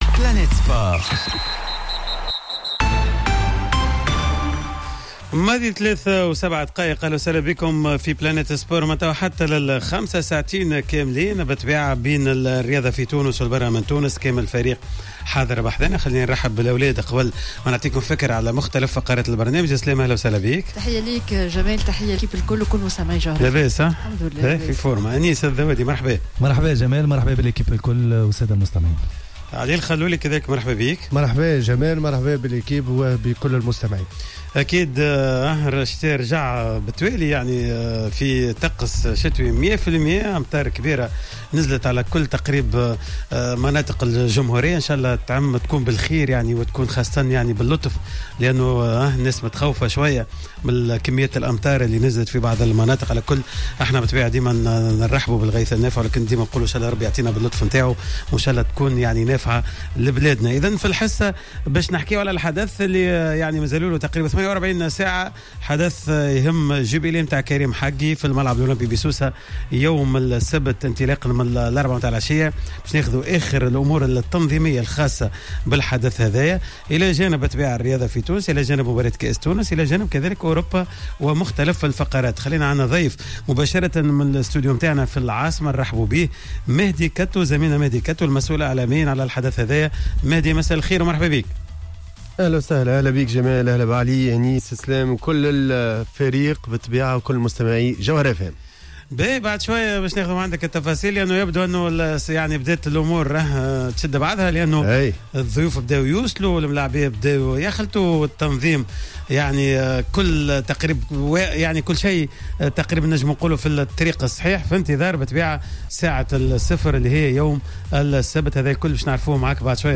من إستوديو جوهرة أف أم بالعاصمة